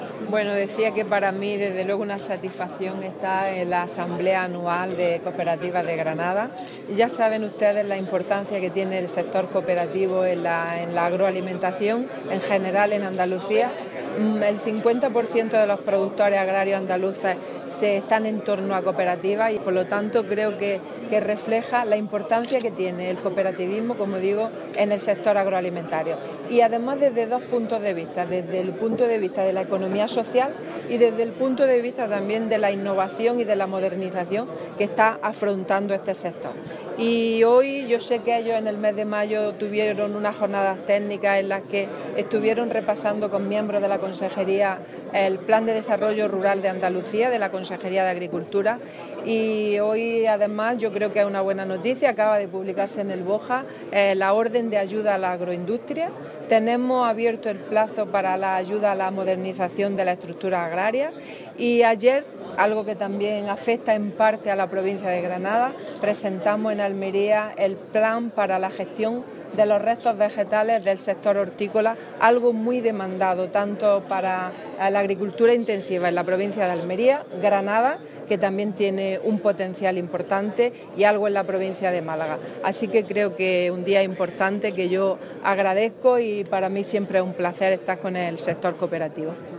Carmen Ortiz en la Asamblea Anual de Cooperativas Agro-alimentarias de Granada
Declaraciones de la consejera sobre cooperativas agroalimentarias